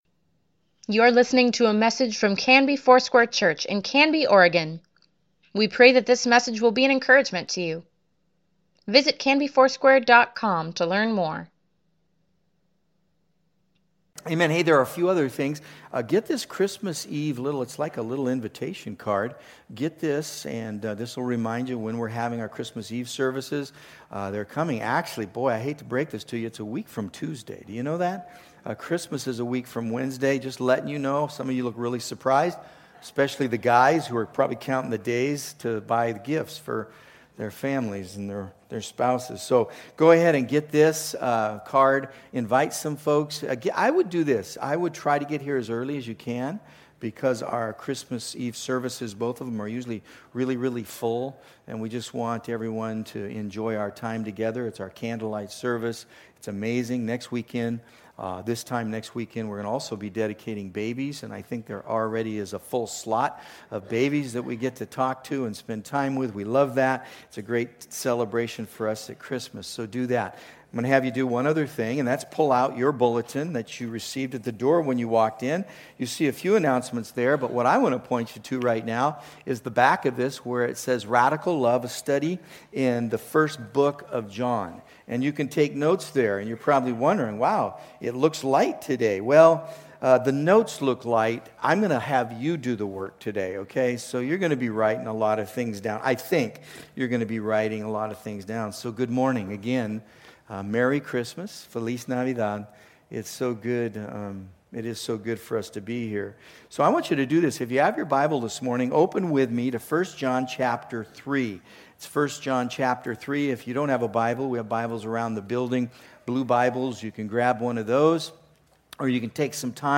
Weekly Email Water Baptism Prayer Events Sermons Give Care for Carus 1 John, pt. 6 December 15, 2019